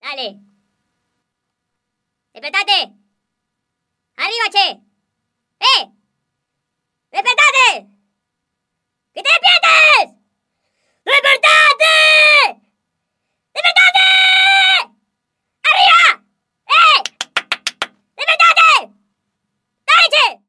Tonos Divertidos